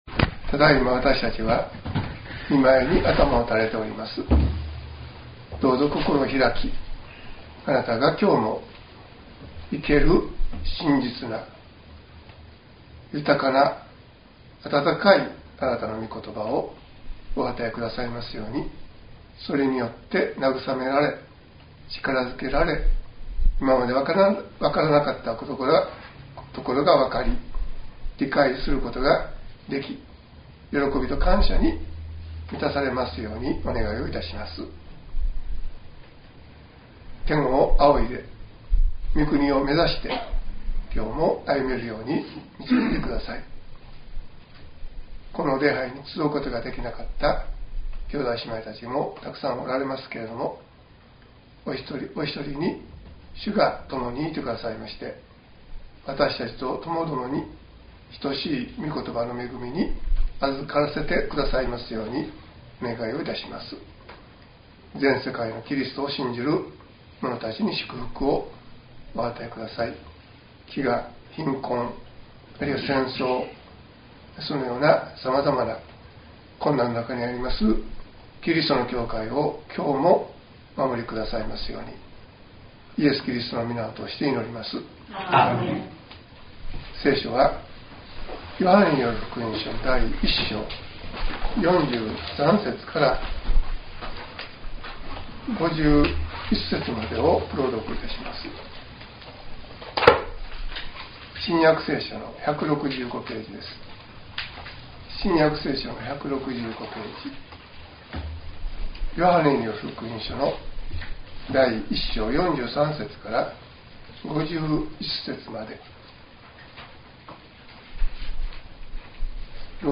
2017年3月26日説教「人の心を知る」